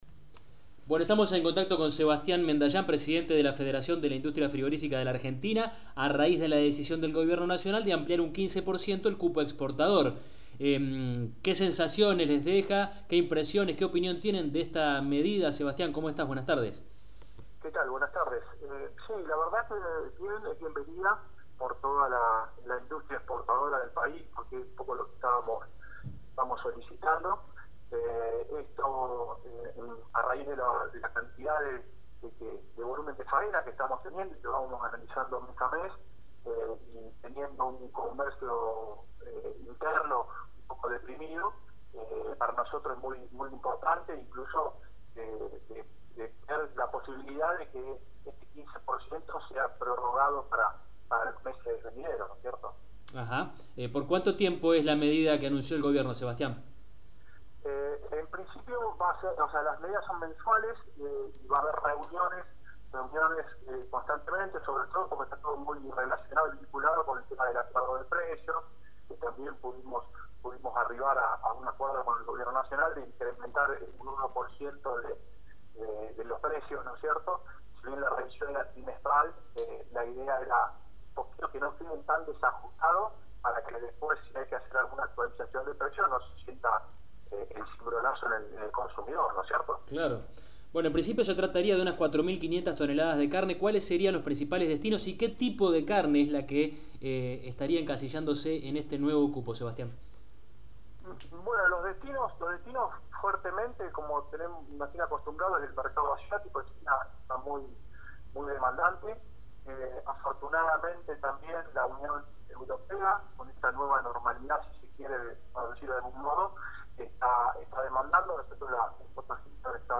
En diálogo con EL CAMPO HOY